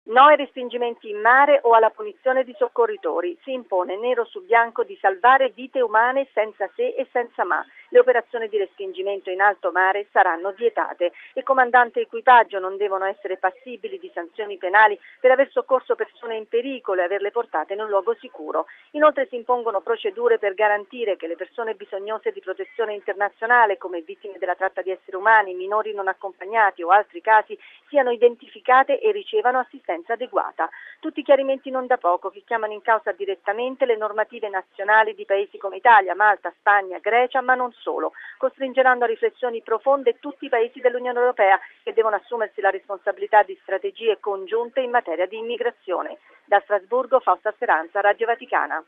Ce ne parla nel servizio da Strasburgo